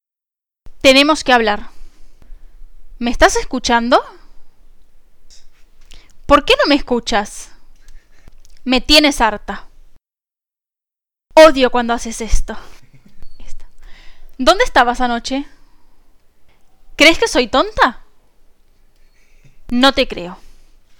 Here are our ‘getting angry’ phrases, but this time with an Argentinian accent.
Notice how the accent is more ‘musical’ than the Madrid one we heard in part 1?